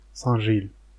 Saint-Gilles (French, pronounced [sɛ̃ ʒil]
Fr-Saint-Gilles.ogg.mp3